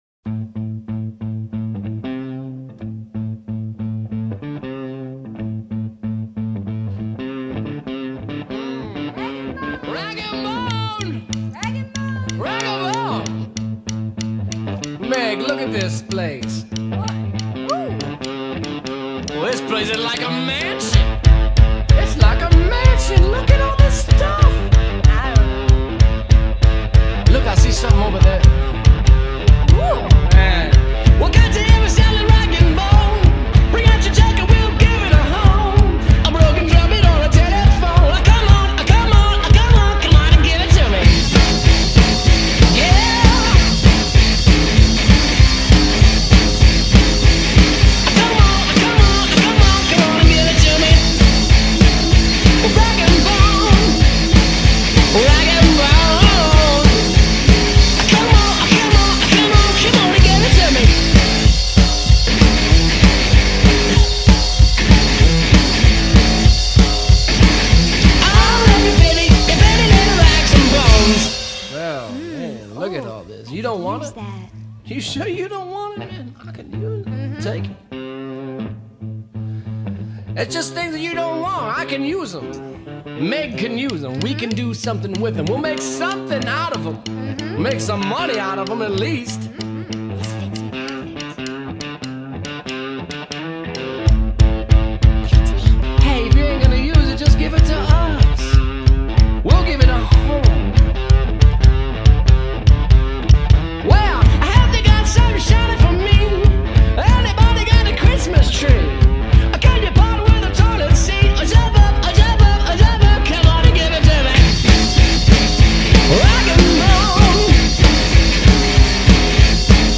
193 BPM ripper